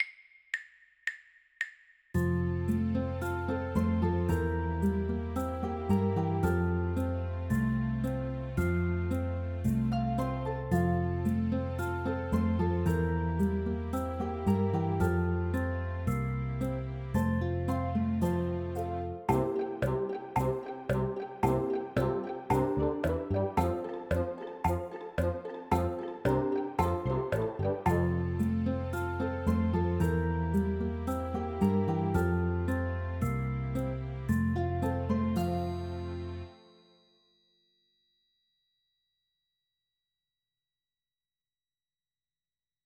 Early Elem